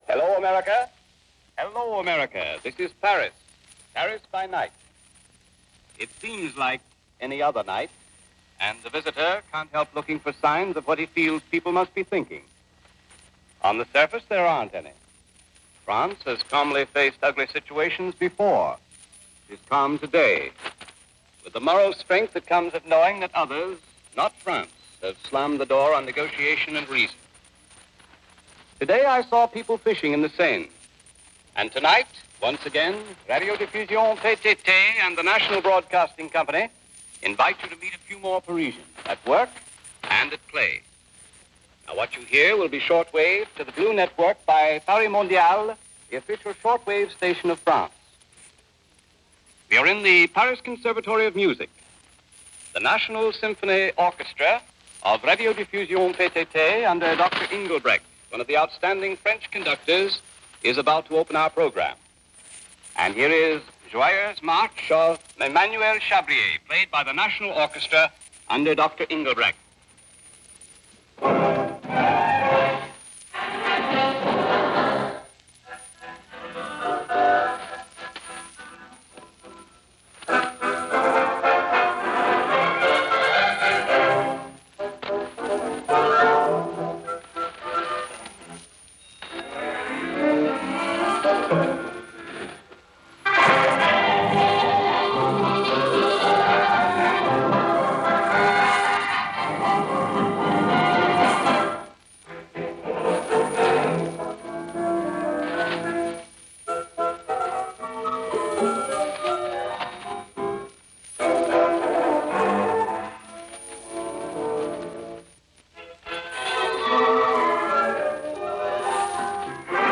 Broadcasting live from Paris and having it picked up via shortwave and broadcast throughout the U.S. by NBC Radio and throughout Canada by The CBC. The premise was simple – a tour of Paris at night, a visit to the hotspots and concert halls for a sampling of what the average Parisian was doing on an average weekend.
The broadcast starts with a portion of a concert from the Paris Conservatory by the Paris Radio Symphony conducted by the legendary D.E. Inglebrecht in music of Emanuel Chabrier. Great performance, but it’s shortwave and it gets dim and overwhelmed with static mid-way through.
The broadcast continues its way through many of the hotspots in Paris offering up some live nightclub appearances by a whole bevy of French performers and music hall stars of the 1930s.